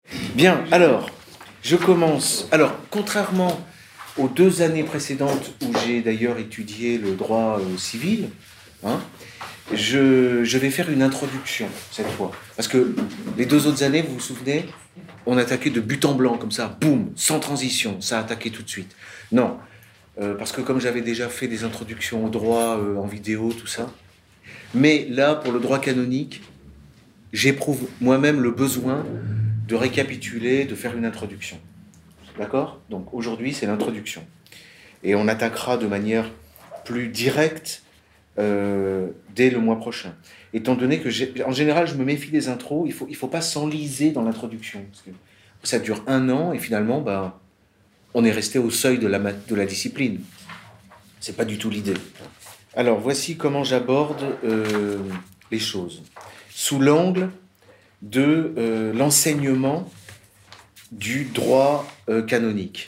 Le séminaire « Comprendre le droit canonique et son histoire » dure deux heure, c’est le live d’un cours de droit que j’ai délivré dans le cadre des Formations d’Egalité et Réconciliation.